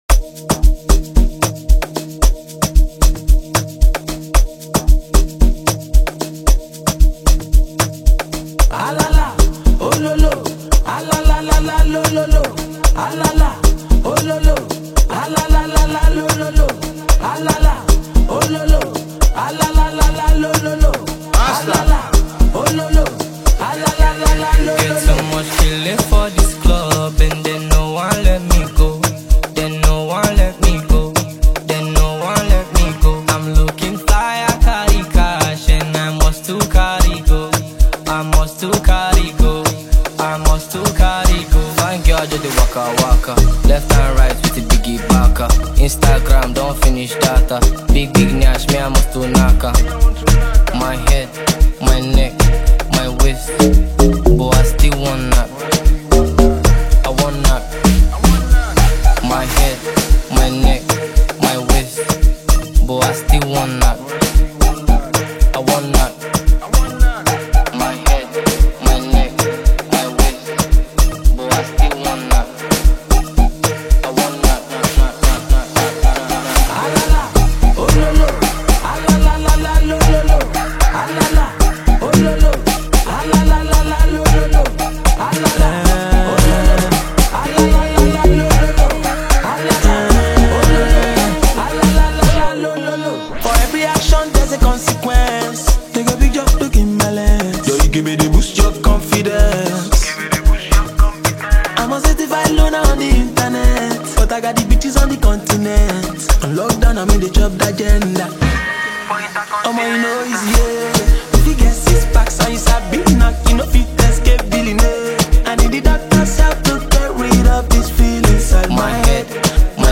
it is a great vibe and a danceable tune